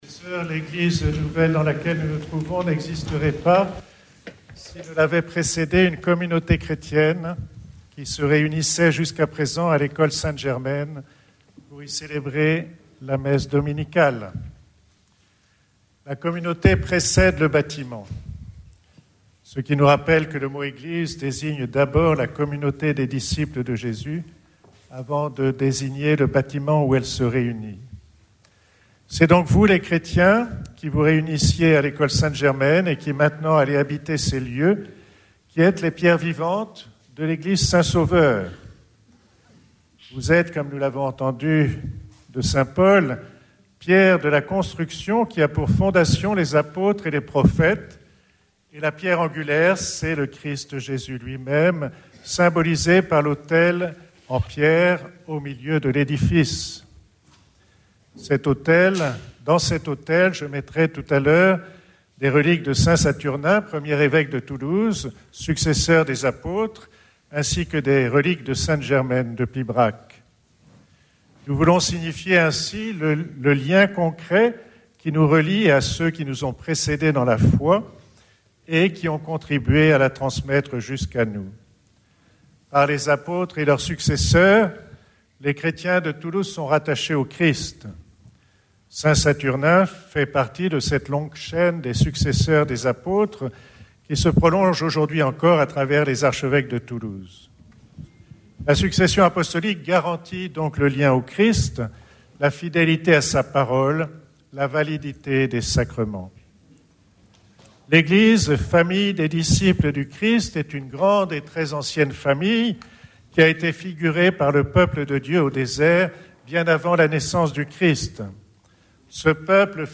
Homélie en direct de la messe de concécration de l’église de Borderouge le 15 décembre 2024